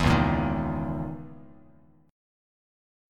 C#m6add9 chord